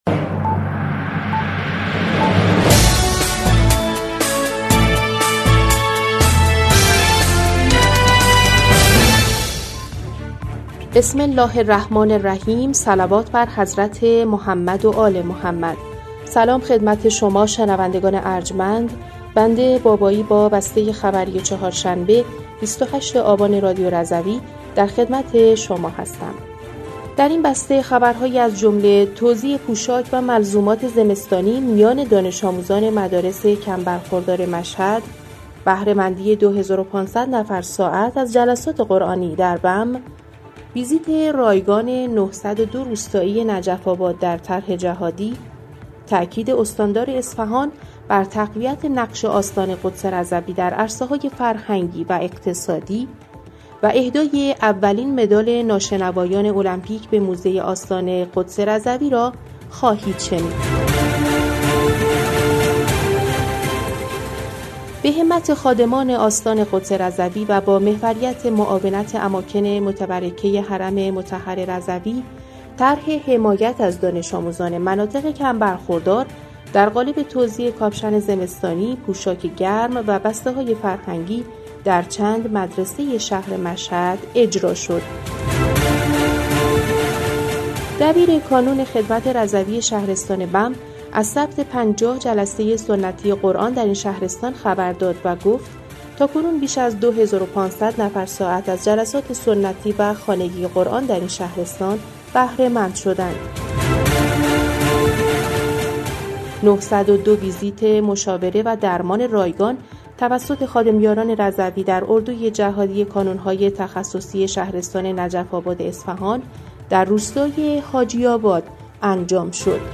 بسته خبری ۲۸ آبان ۱۴۰۴ رادیو رضوی؛